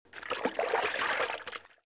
water07.mp3